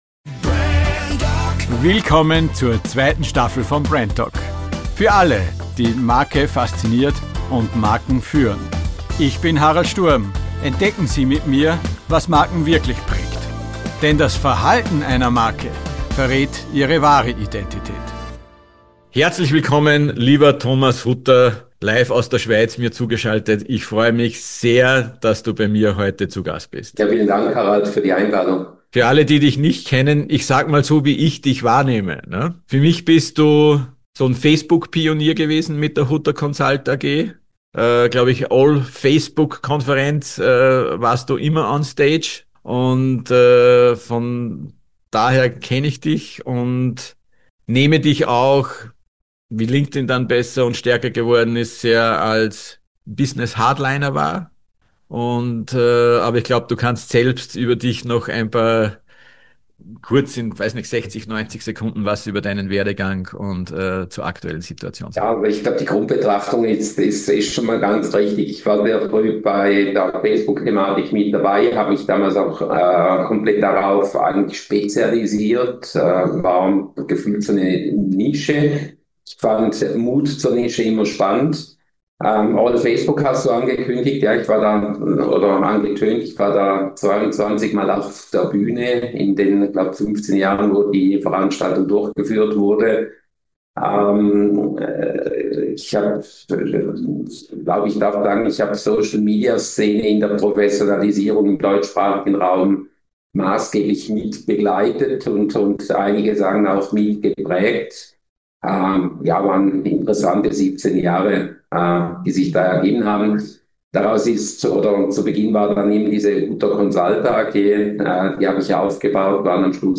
Corporate Podcast Produktion